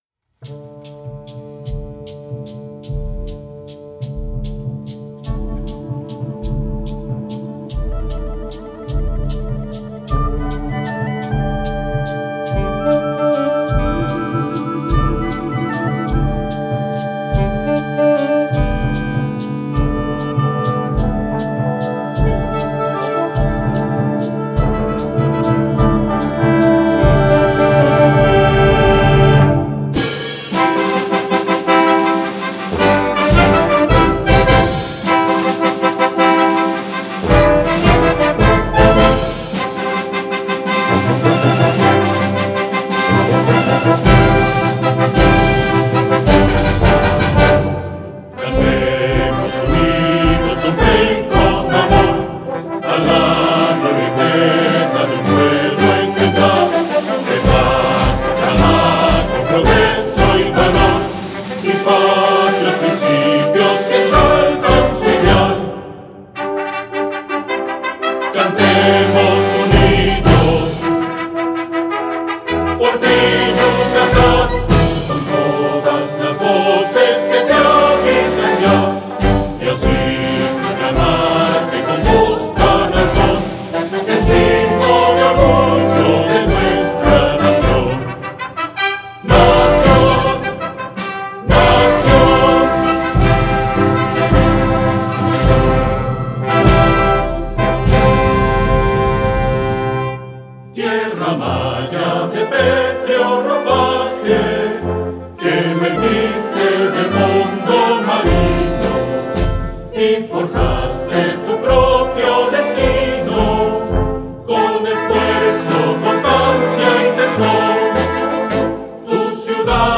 Letra-y-musica-del-Himno-de-Yucatan-en-banda.mp3